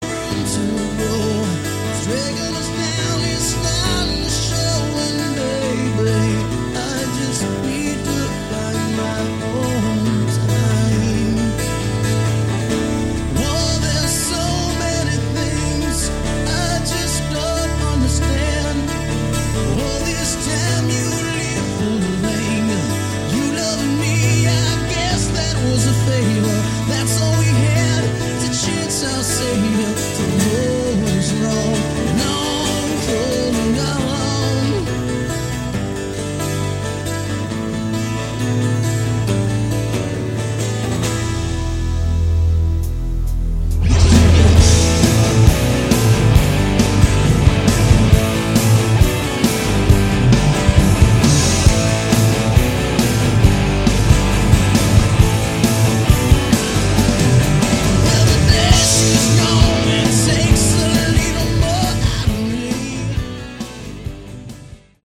Category: Hard Rock
lead vocals
guitar
bass
drums